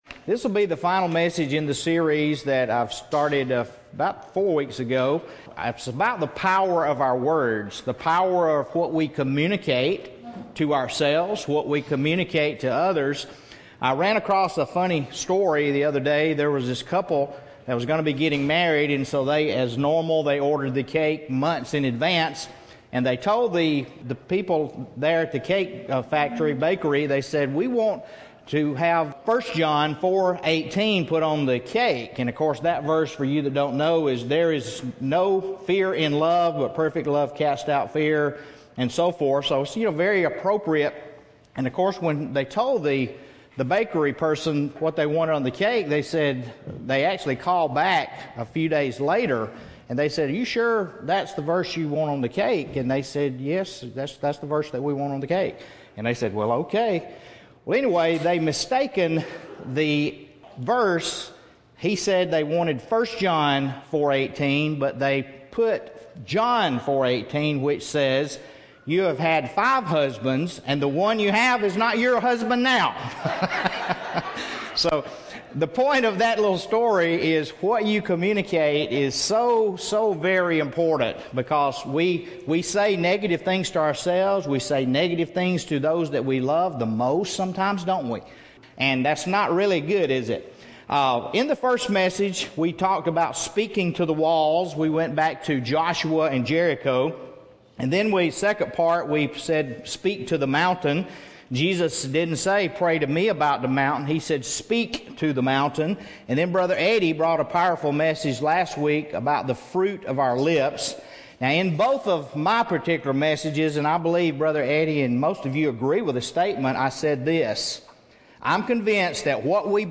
The scriptures used today were John 1:17; Romans 10:5-6; Ephesians 4:29 The Video shown during the service today was by Florence Littauer.